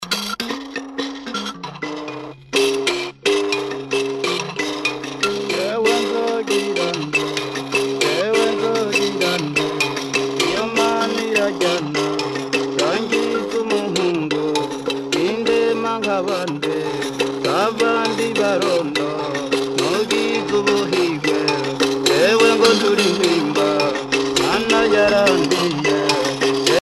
Solo de sanza avec voix